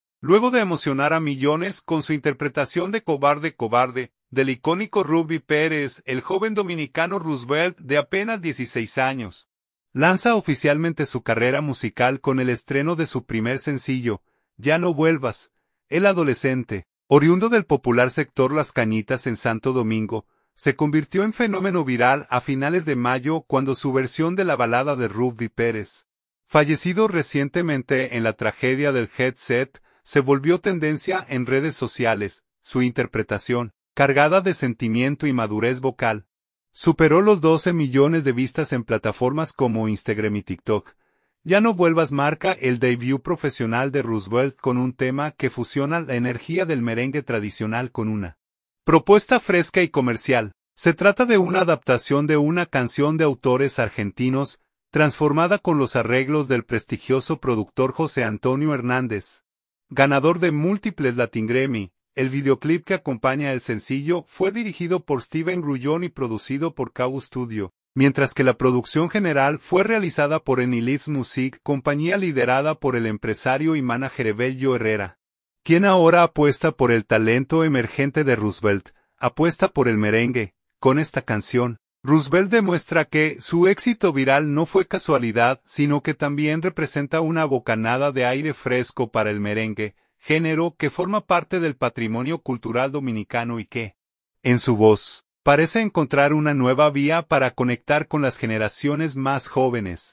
Apuesta por el merengue